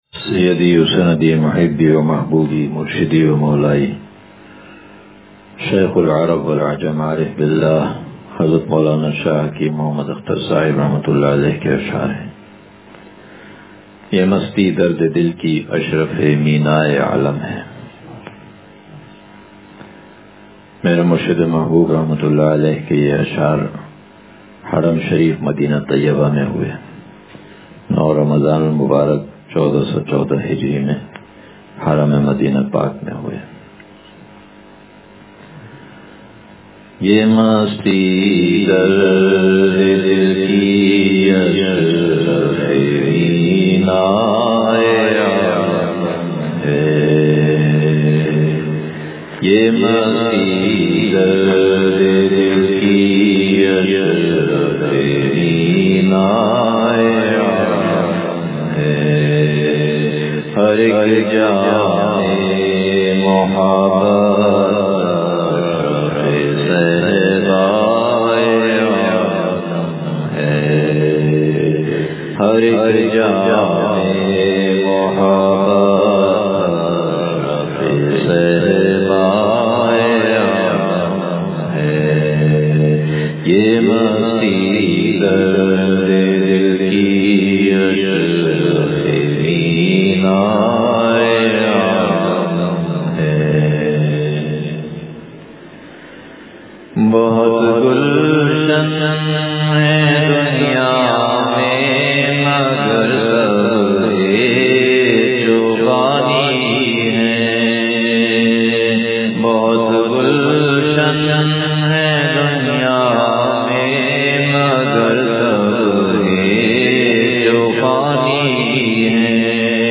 یہ مستی درد دل کی اشرف میناےً عالم ہے – مجلس بروز اتوار